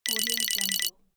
Score_Count_1.mp3